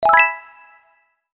Index of /ILoveULobbyResource/sounds/sound-effect/
SWIPE.wav